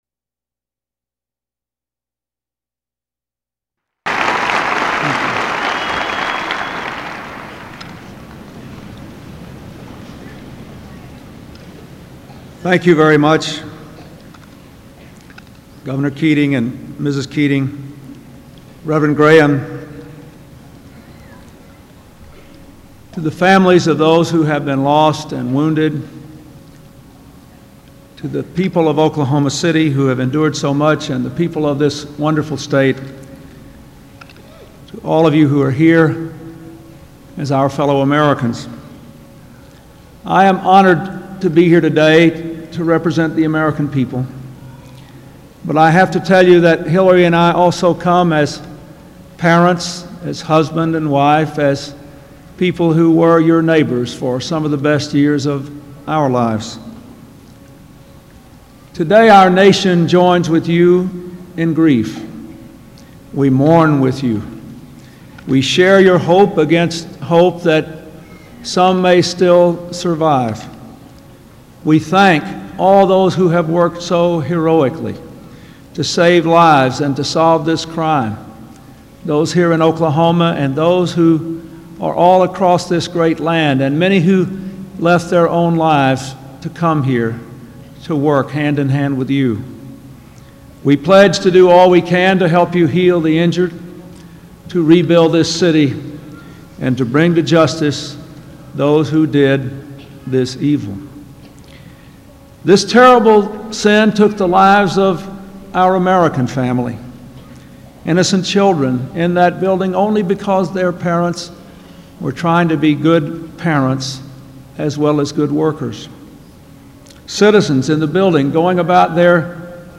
Presidential Speeches
April 23, 1995: Time for Healing Ceremony